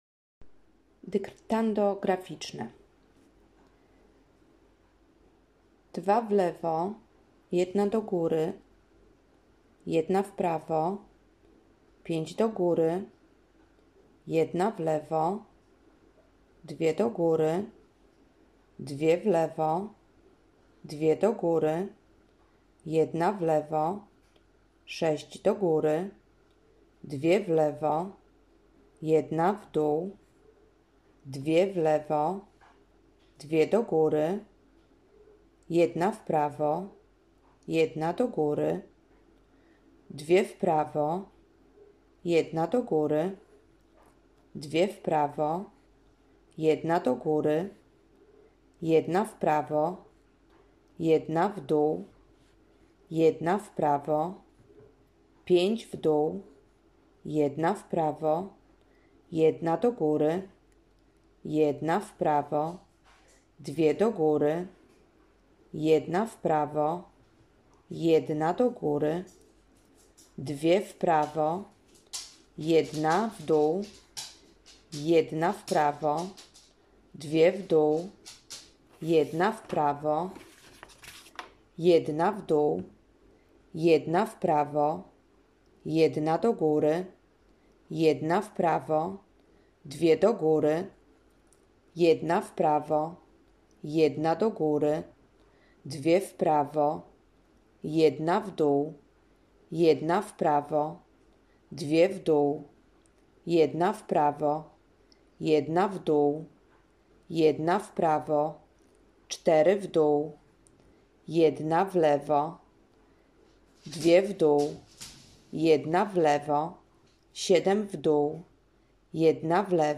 dyktando_graficzne_onlineaudioconvertercom.mp3